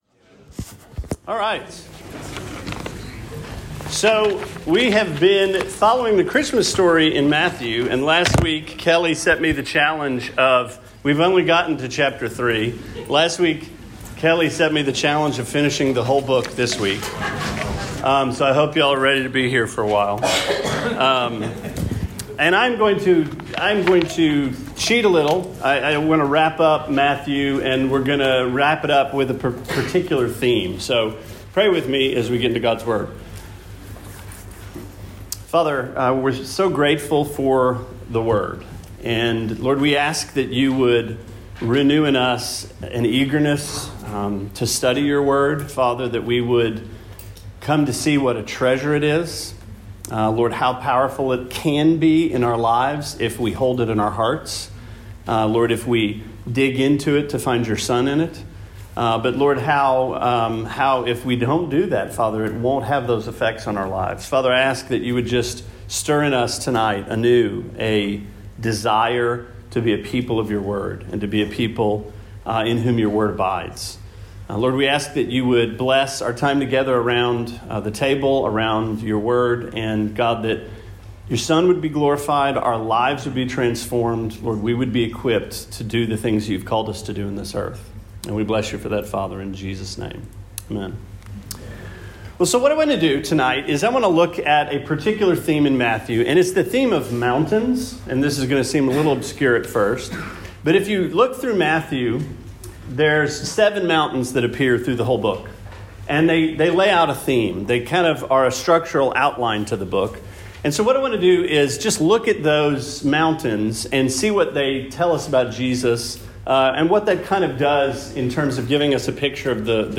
Sermon 12/27: Mountains in Matthew